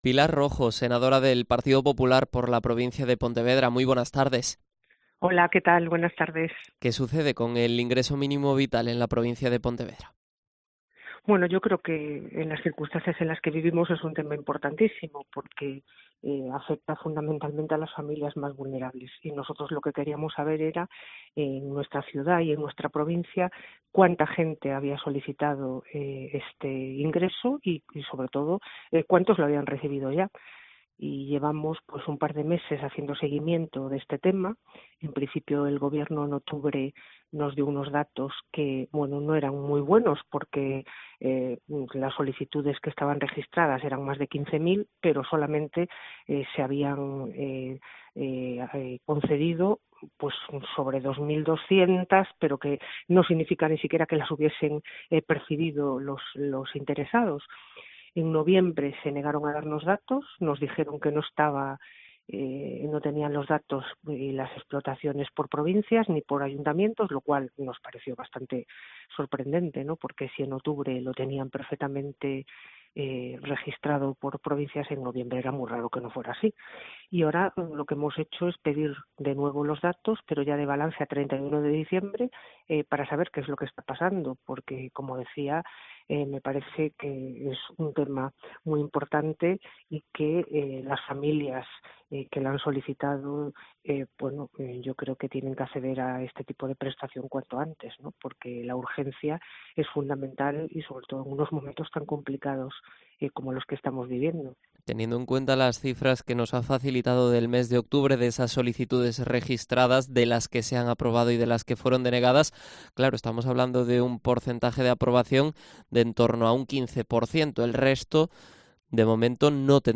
Entrevista a Pilar Rojo, senadora del PP de Pontevedra